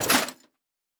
Foley Armour 06.wav